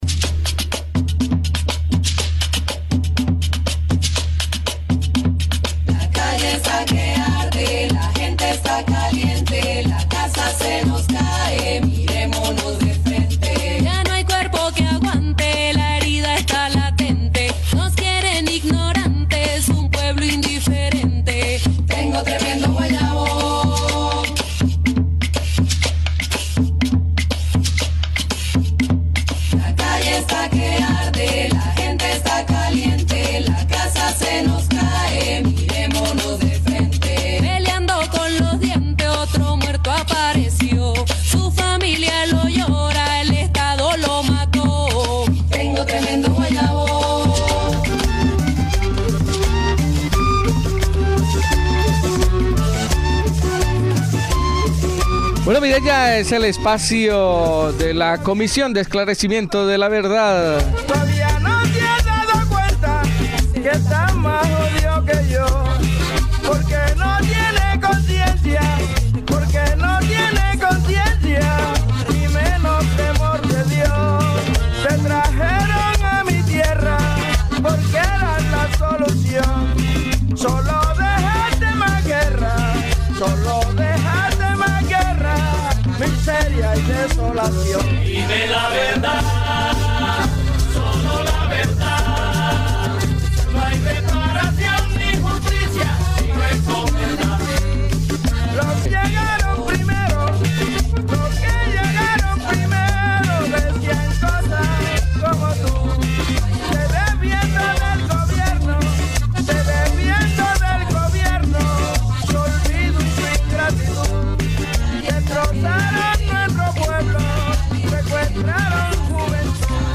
Iniciamos el programa de la Comisión de Esclarecimiento de la Verdad-CEV en Kaixo Latinoamerika- Hola Latinoamerica de Hala Bedi Irratia en el País Vasco con la participación del Comisionado Carlos Beristain